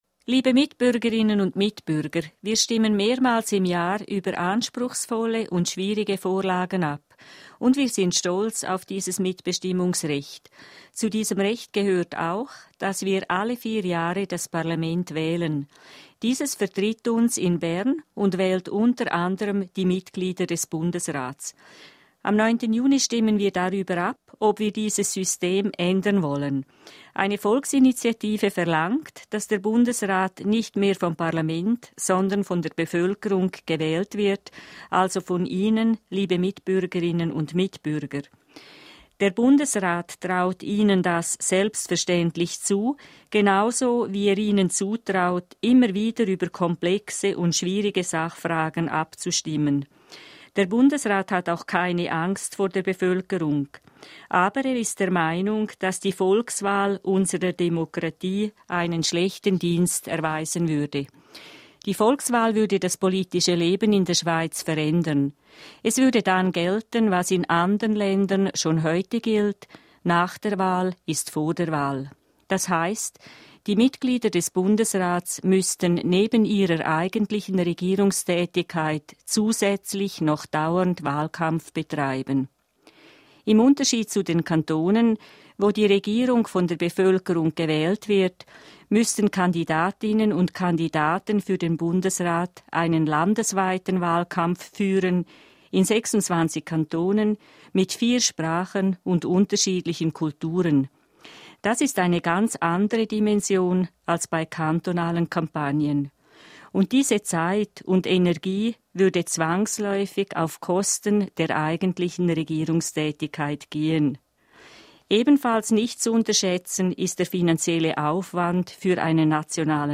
Bundesrätin Eveline Widmer Schlumpf
Medienkonferenz des Bundesrats vom 26.03.2018